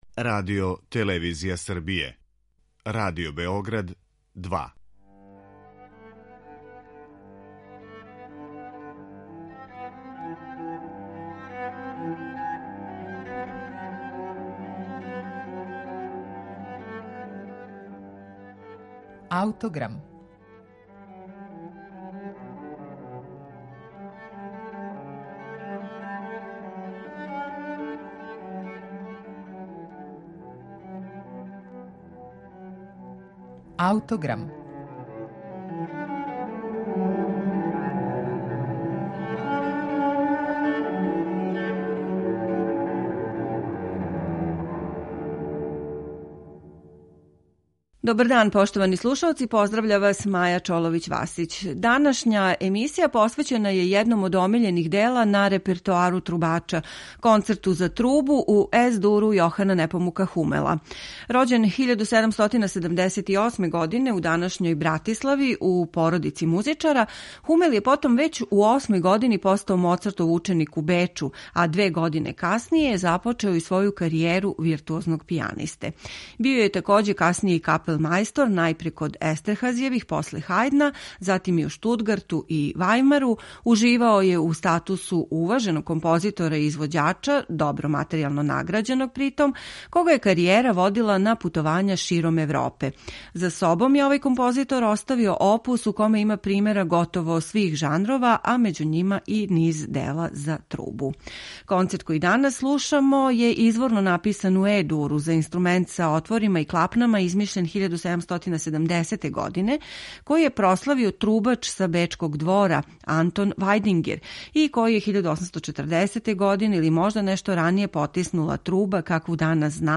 Реч је о делу писаном за нови тип инструмента из осме деценије XVIII века које је премијерно представљено публици тек 1803. године. Концерт уобичајене троставачне структуре и препознатљивог класичарског израза слушаћете у интерпретацији славног Мориса Андреа.